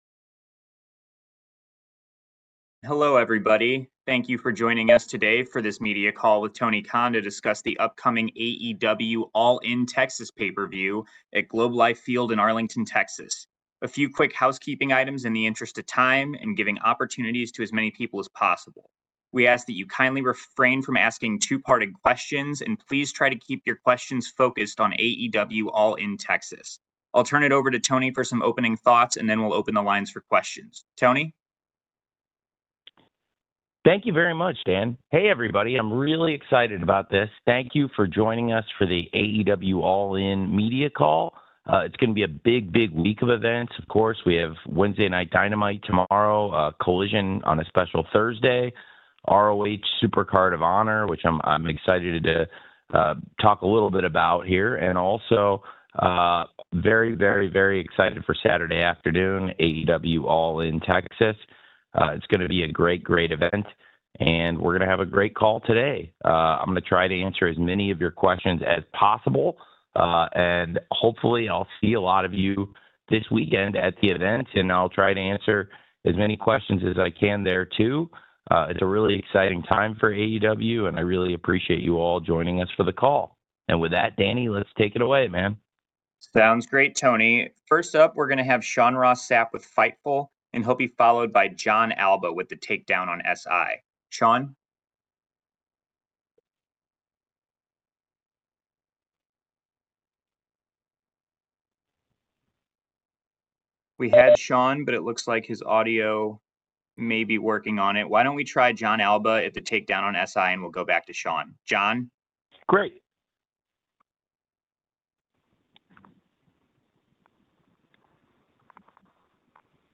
The audio from the Tony Khan media call about All In Texas and ROH Supercard of Honor 2025.